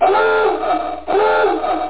1 channel
alarm.mp3